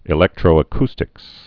(ĭ-lĕktrō-ə-kstĭks)